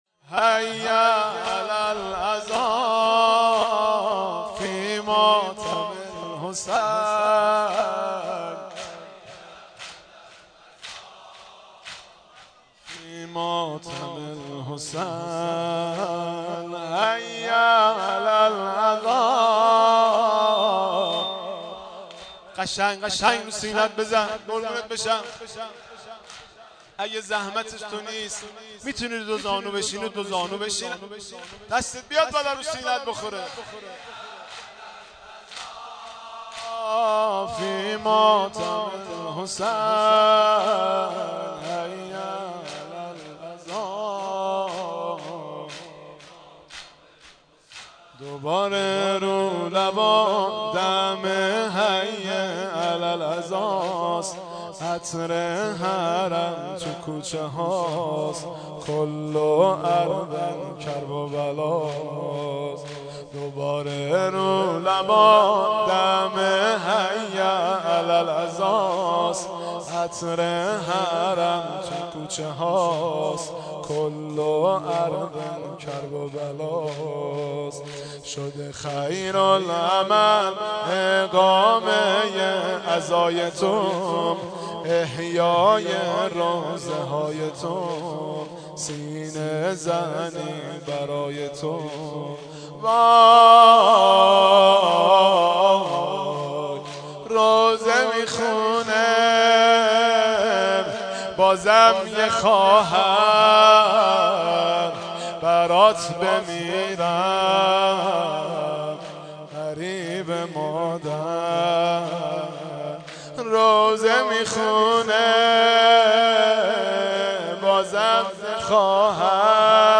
زمینه زیبا